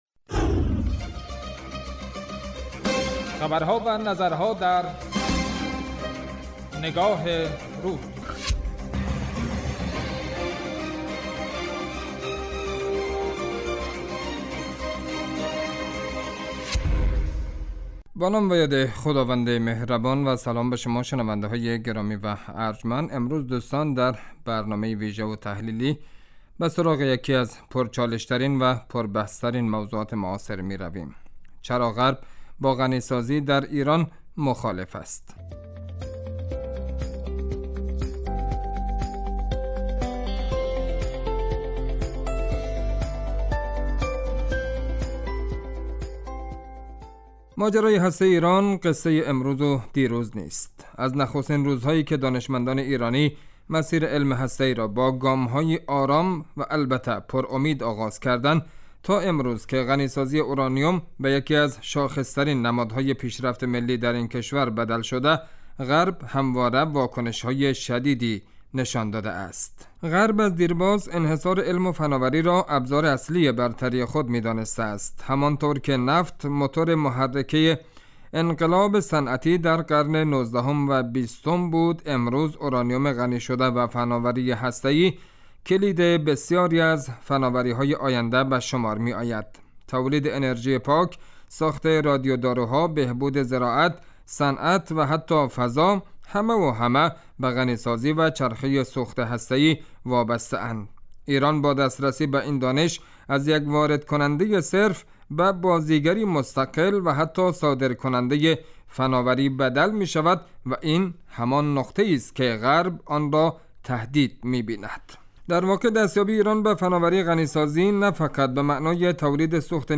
اطلاع رسانی و تحلیل و تبیین رویدادها و مناسبتهای مهم ، رویکرد اصلی برنامه نگاه روز است .